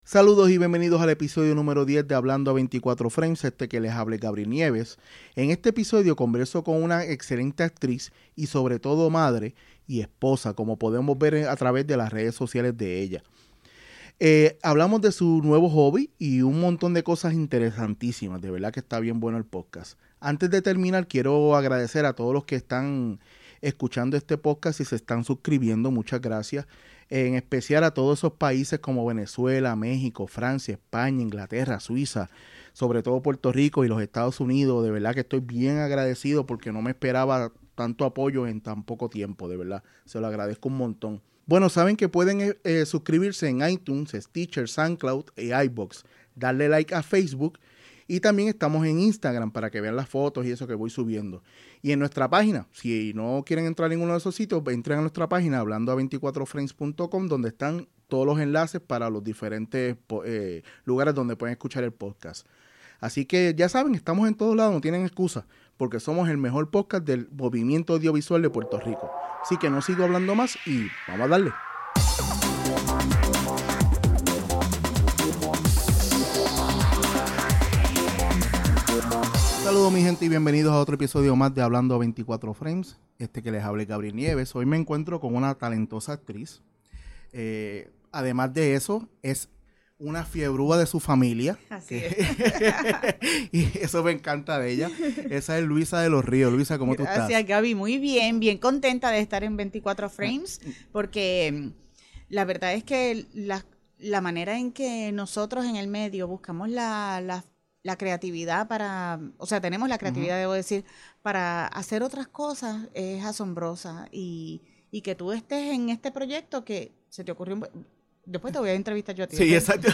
En este episodio # 10 hablo con una excelente actriz y sobre todo madre y esposa como podemos ver en sus redes sociales, también hablamos sobre su nuevo hobby y de un monton de cosas más que son super interesantes.